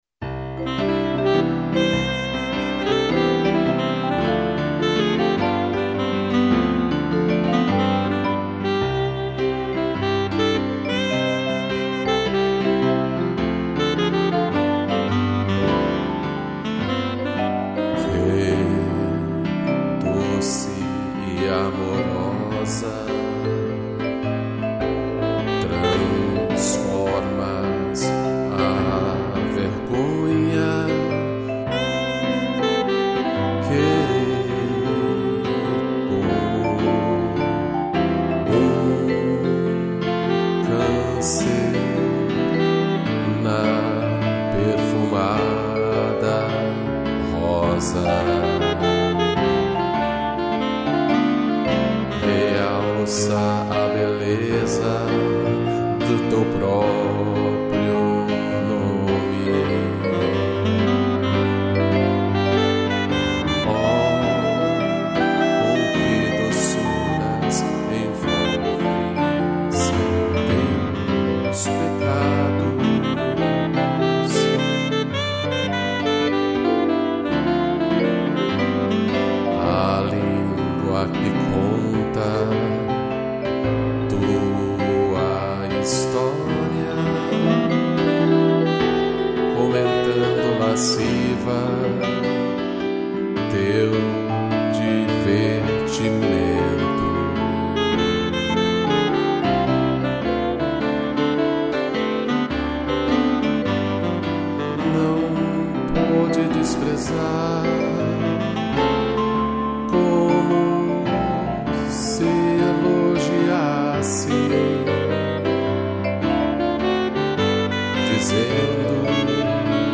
vibrafone, piano e sax